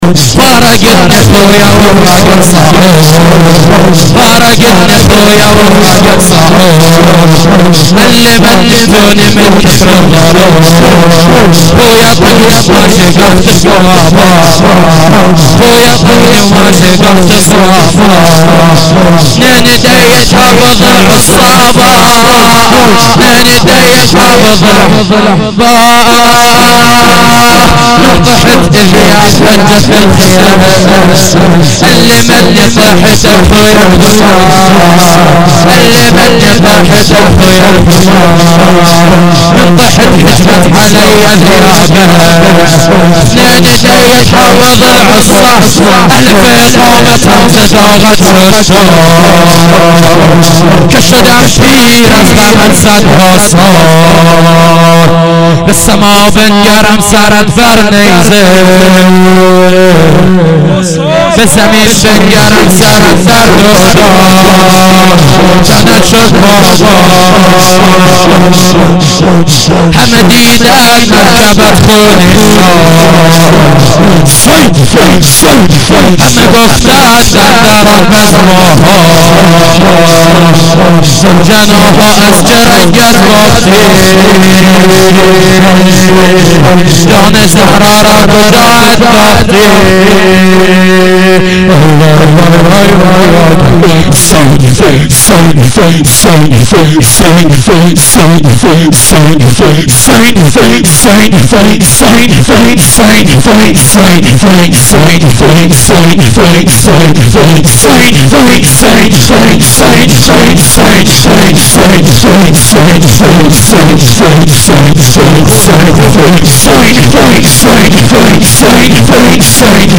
شور عربی
fatemieh-aval-92-shab2-shor-arabi-farsi.mp3